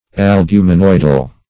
Albuminoidal \Al*bu`mi*noid"al\, a.